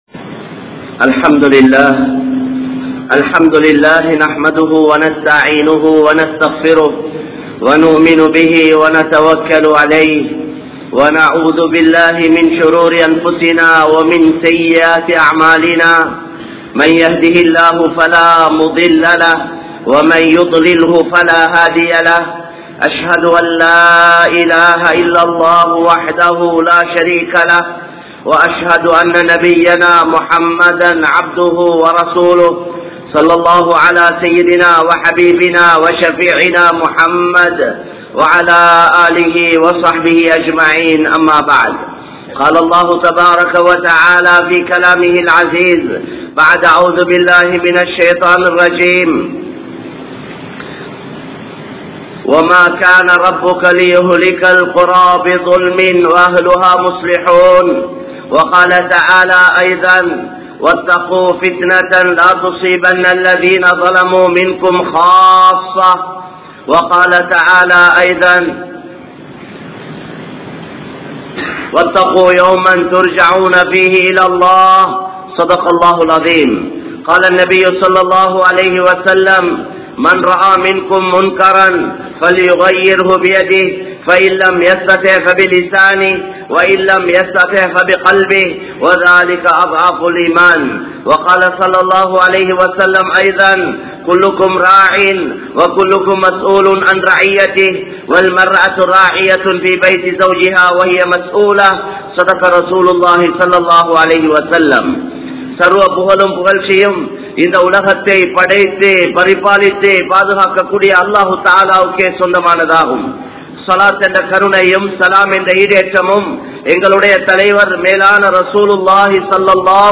Allah`vin Athisayamaana Padaippu Manithan (அல்லாஹ்வின் அதிசயமான படைப்பு மனிதன்) | Audio Bayans | All Ceylon Muslim Youth Community | Addalaichenai
Puttalam, Kottantivu, Muhiyadeen Jumua Masjidh